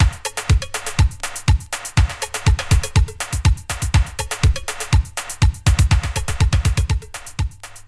• 122 Bpm 80s Breakbeat B Key.wav
Free drum loop - kick tuned to the B note. Loudest frequency: 1695Hz
122-bpm-80s-breakbeat-b-key-iWf.wav